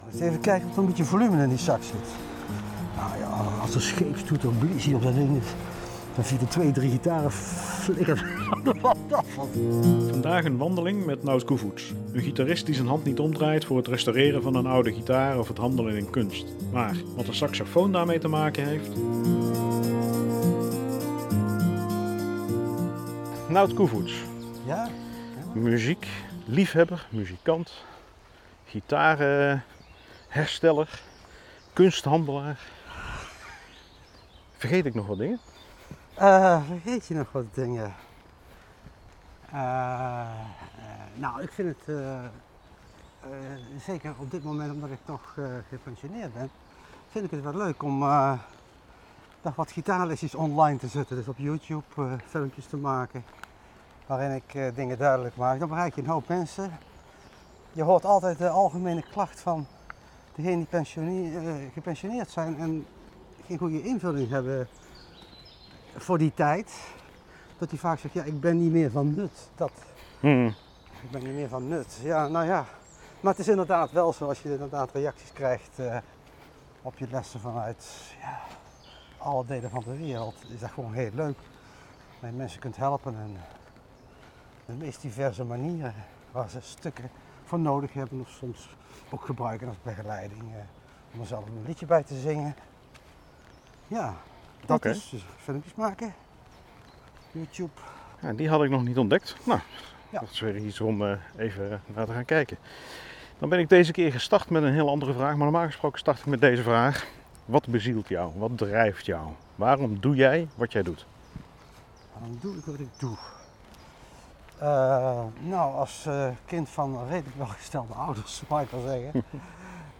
Deze voorlaatste wandeling van dit seizoen is wederom op het zeer populaire Landgoed Oosterheide.